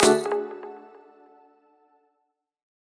melodic-3.wav